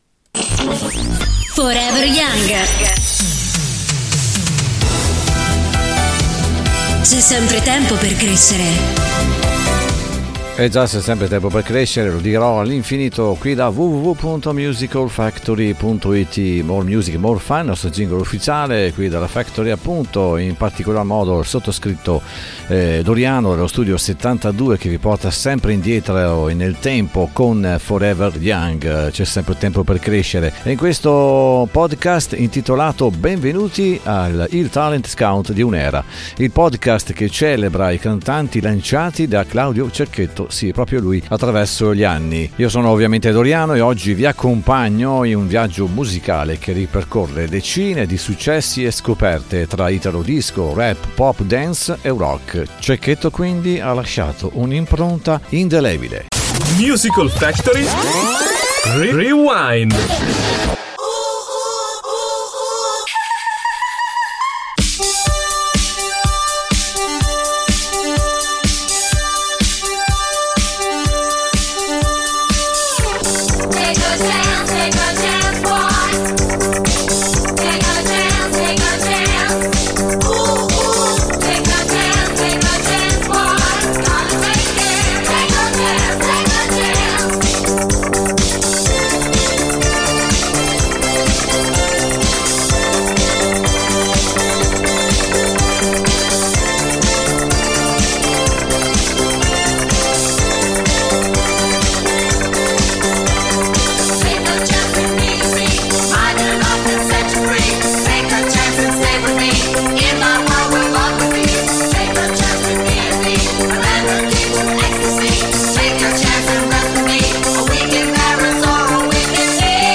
italo-disco, rap, pop, dance e rock